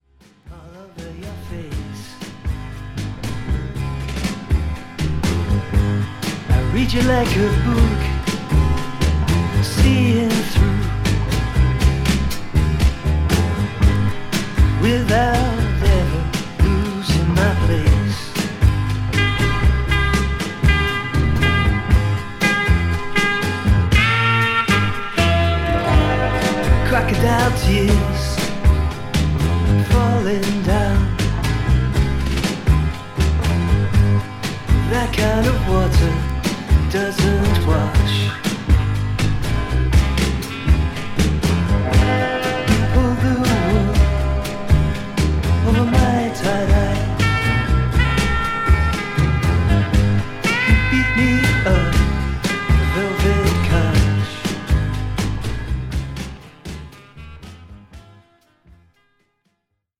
内省的でパーソナルな陰影が際立つS&SWとしての魅力にも溢れた、UKインディ・ロック〜ネオアコの好盤です。